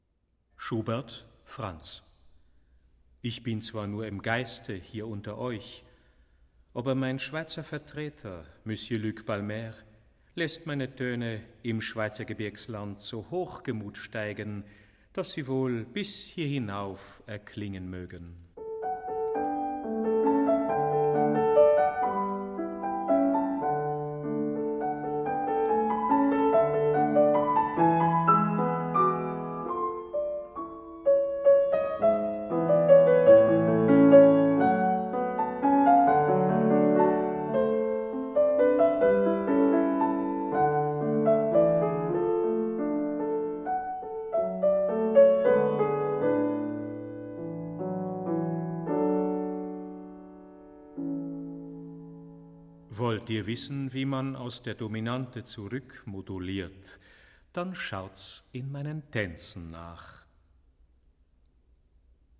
Albert Moeschinger: Franz Schubert, - (piano)